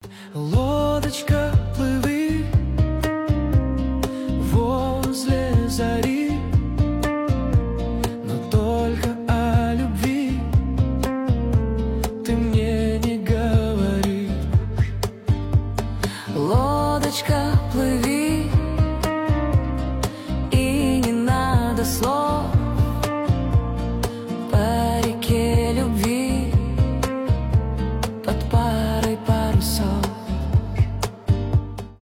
нейросеть , дуэт , романтические , поп , cover